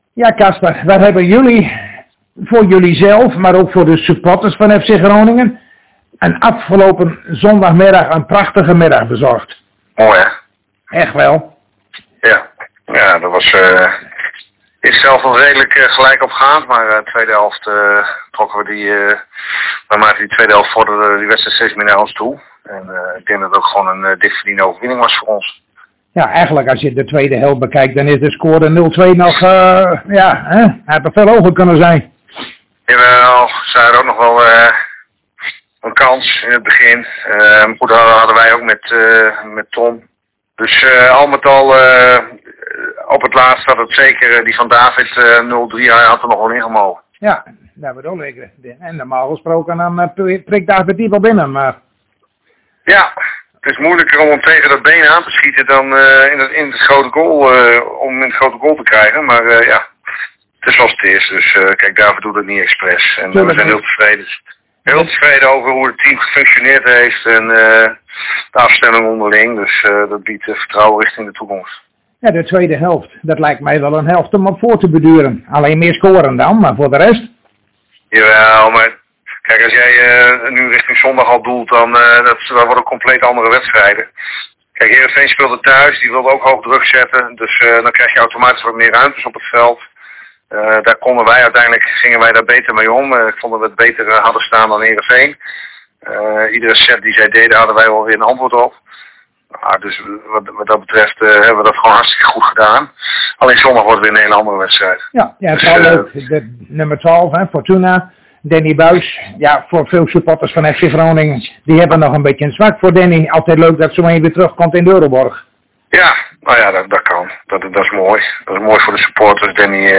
Zojuist spraken wij met Casper Goedkoop en uiteraard keken we even terug op de winst tegen SC Heerenveen maar keken ook vooruit op de wedstrijd van zondag a.s. tegen Fortuna Sittard.